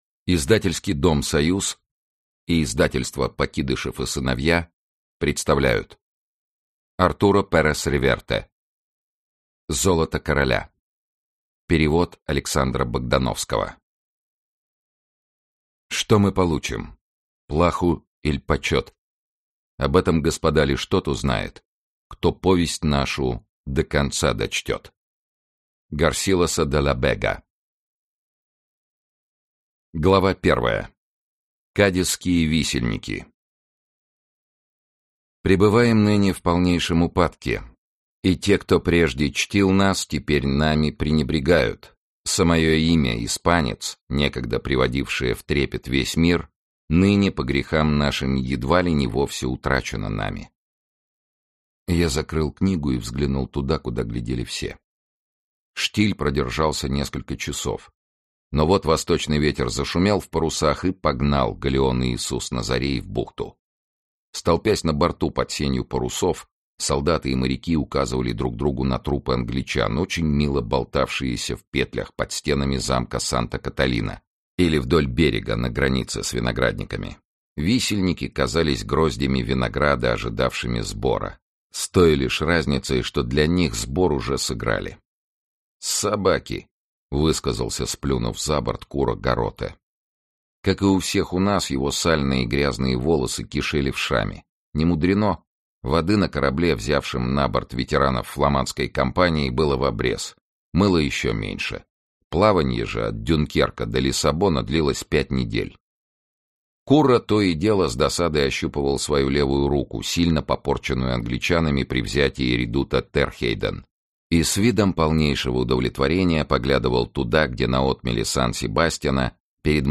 Аудиокнига Золото короля | Библиотека аудиокниг
Aудиокнига Золото короля Автор Артуро Перес-Реверте Читает аудиокнигу Сергей Чонишвили.